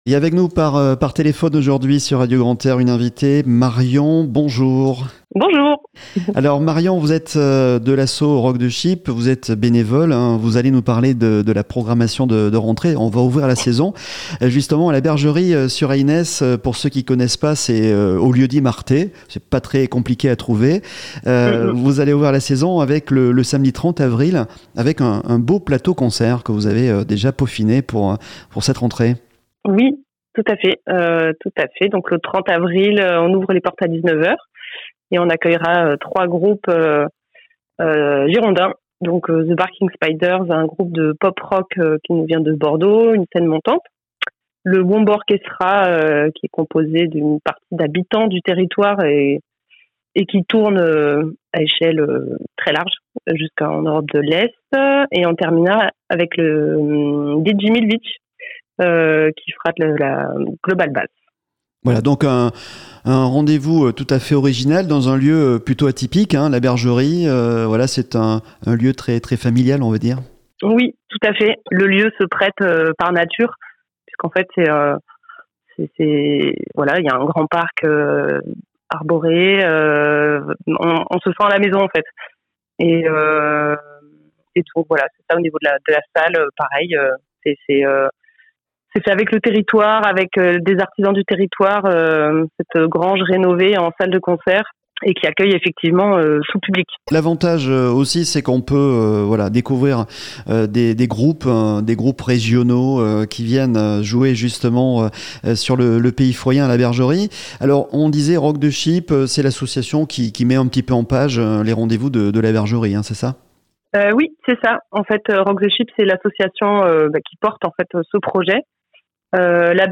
Le podcast des invités de Radio Grand "R" !